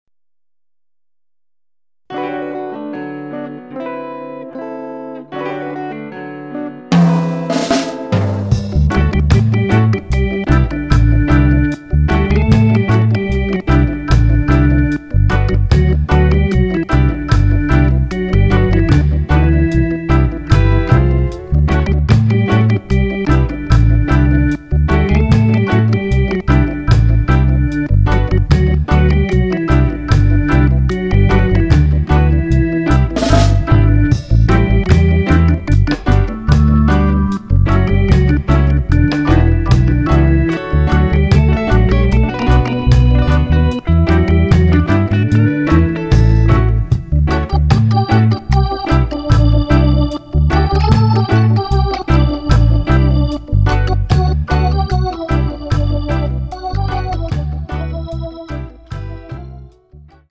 原曲の気怠いムードをルーディーでリラックスした演奏で再現したインスト・オルガン・ナンバーに仕上がっています！
こちらは日本語ロックの古典がレイドバックしたロックステディに生まれ変わっています！